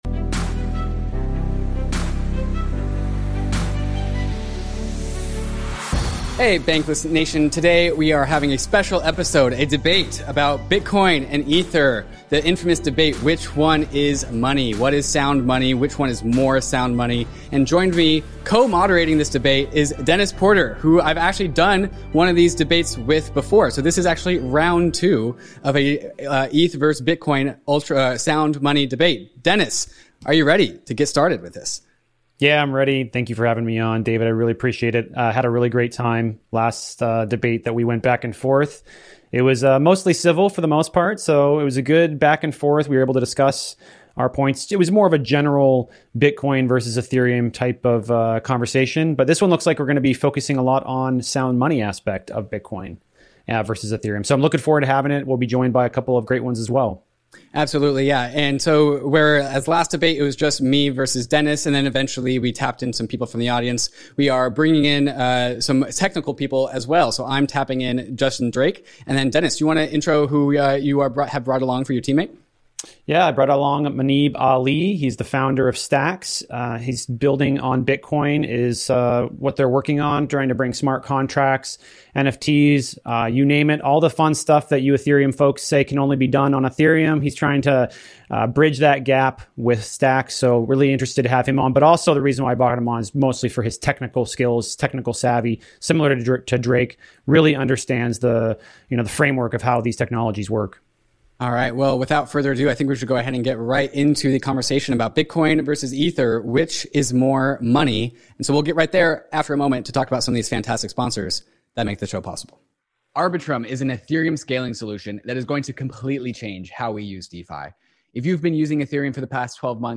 DEBATE: BTC vs ETH; Which is more Sound Money?